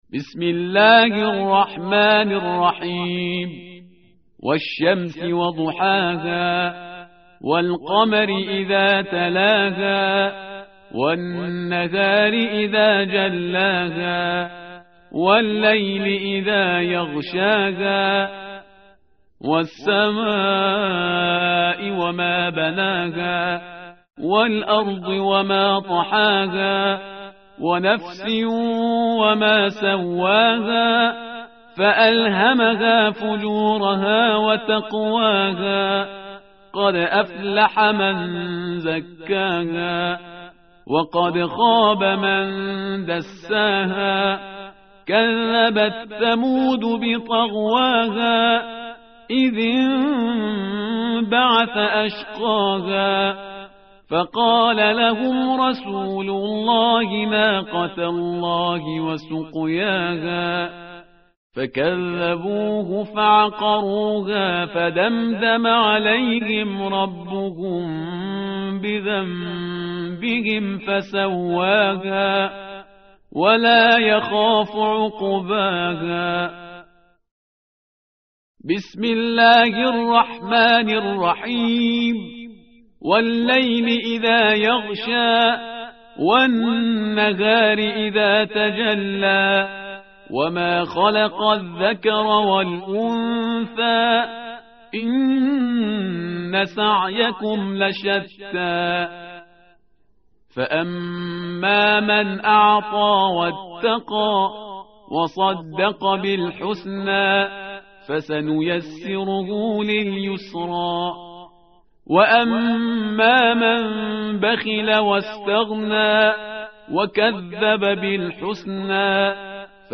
tartil_parhizgar_page_595.mp3